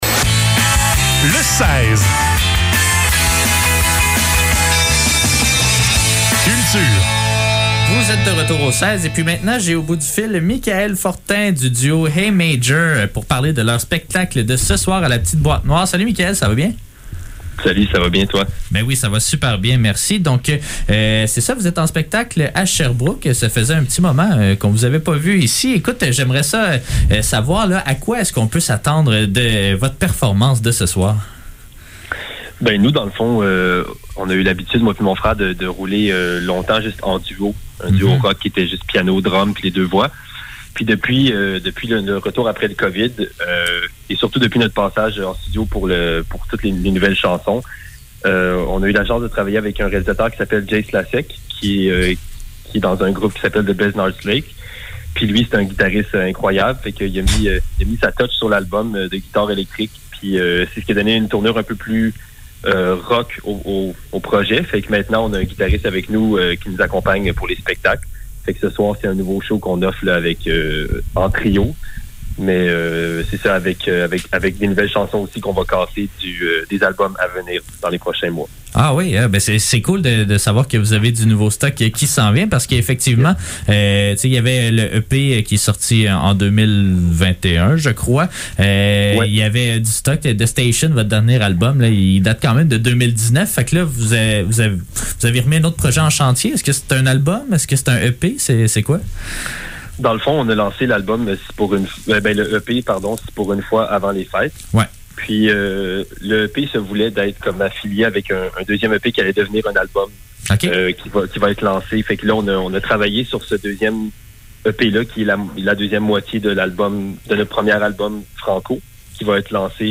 Le seize - Entrevue avec Hey Major - 31 mars 2022